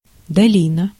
Ääntäminen
IPA : /ˈvæli/